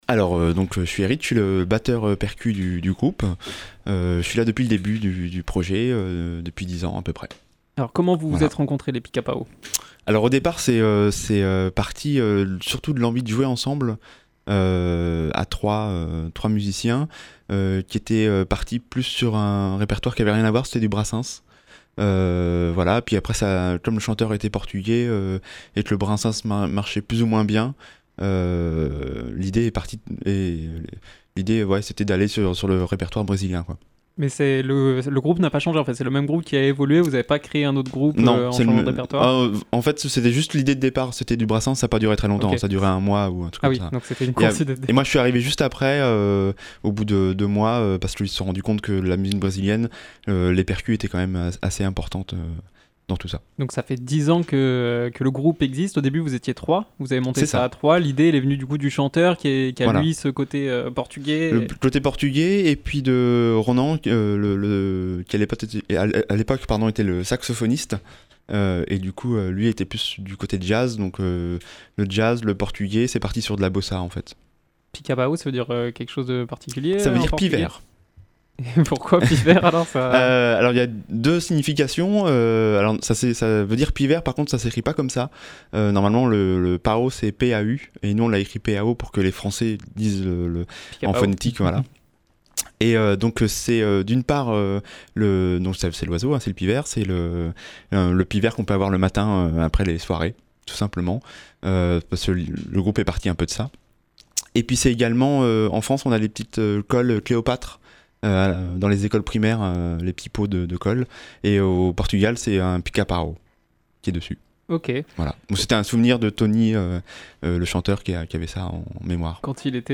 Musiques traditionnelles brésiliennes revisitées !
L’occasion de se laisser emporter par dix titres aux sonorités brésiliennes !